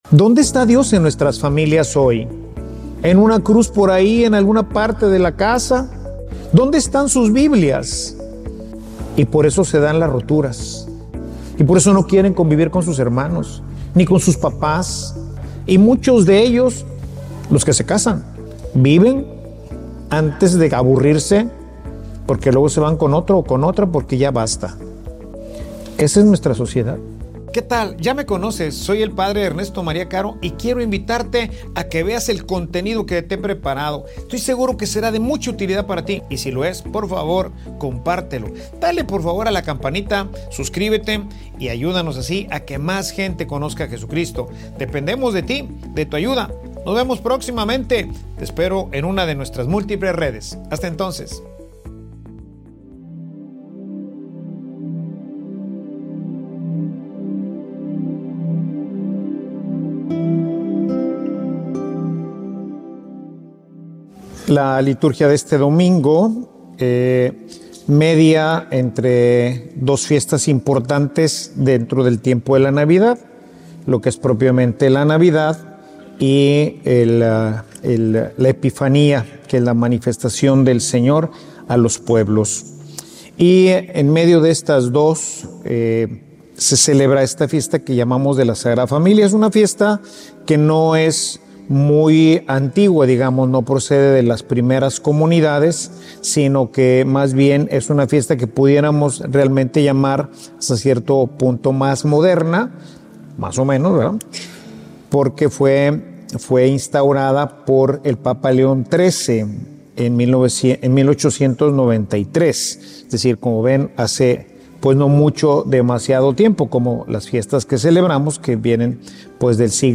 Homilia_Familia_unida_a_Dios_es_invencible.mp3